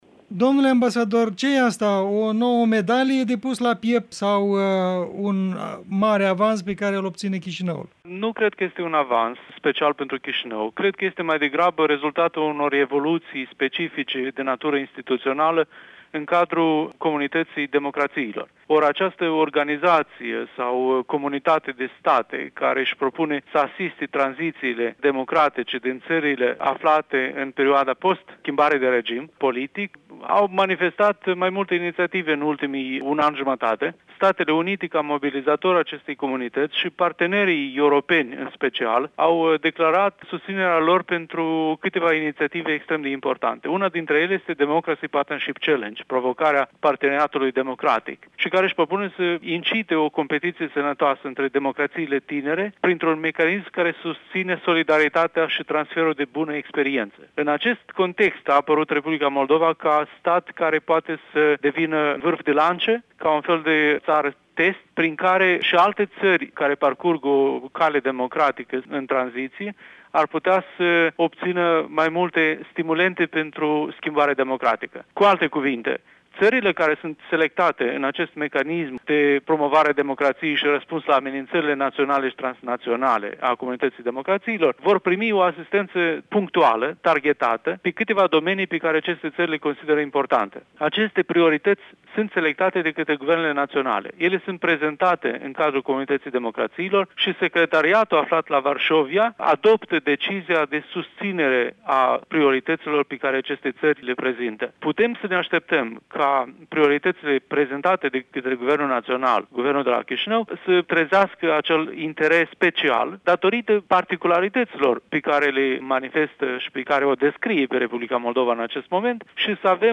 Un interviu cu ambasadorul Republicii Moldova în SUA, Igor Munteanu